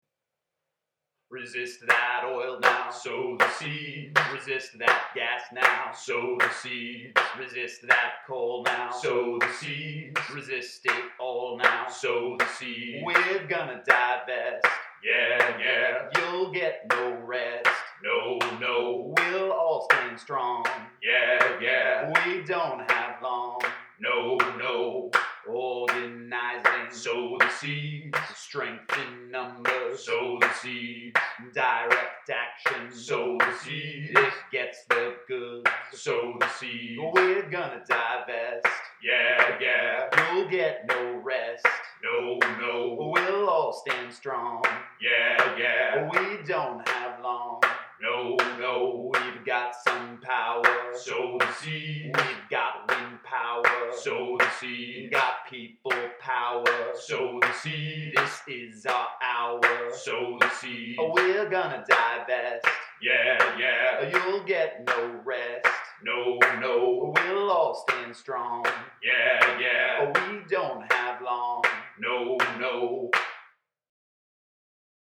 Goofy MP3 Version